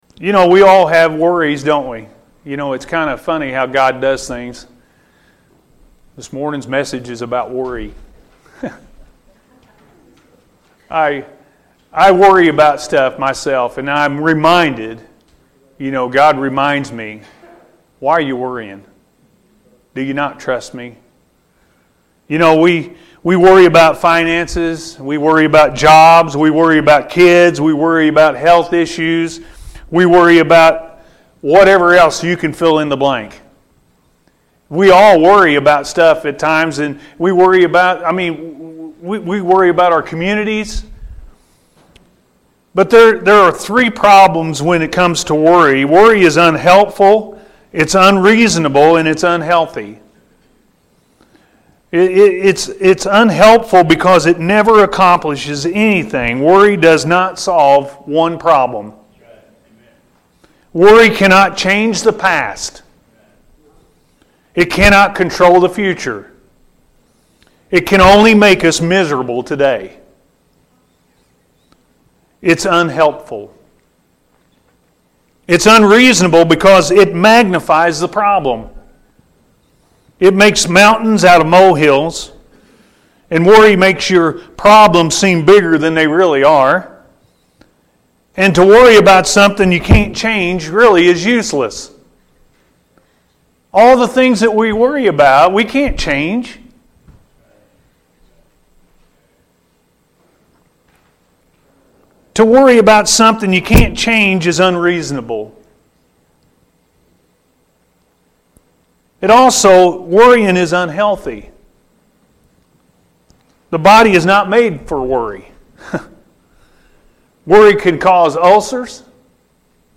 Jesus Is Our Shepherd-A.M. Service – Anna First Church of the Nazarene